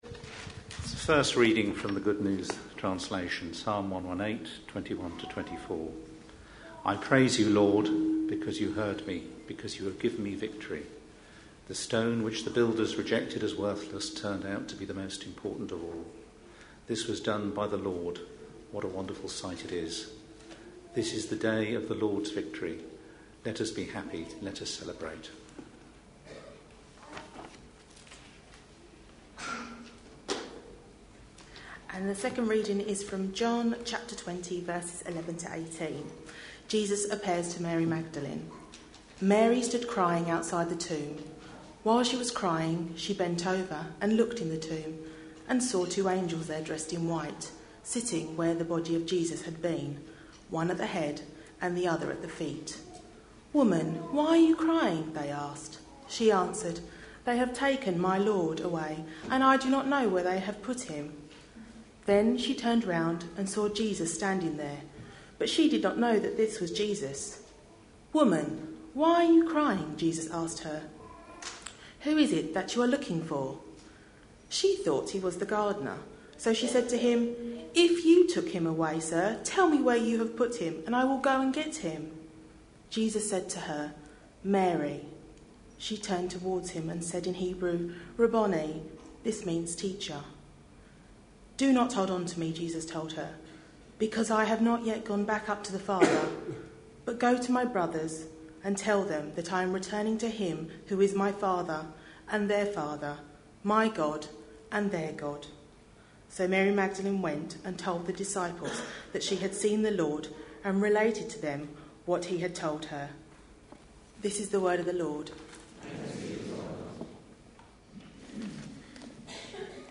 A sermon preached on 7th April, 2013, as part of our Three things the risen Jesus said: series.